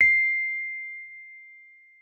sounds / Rhodes_MK1 / c6.mp3
c6.mp3